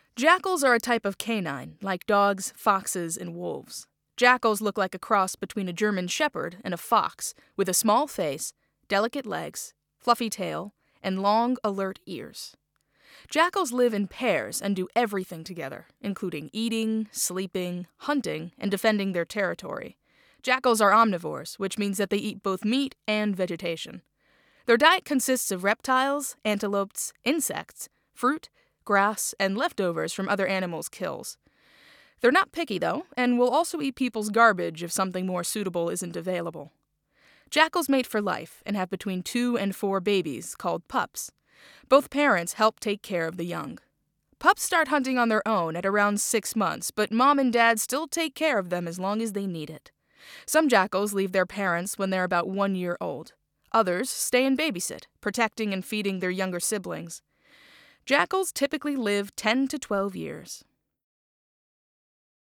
• בכמה מקטעי השמע תשמעו אדם אחד מדבר, ובכמה מהם תשמעו שיחה בין שני אנשים - אישה וגבר.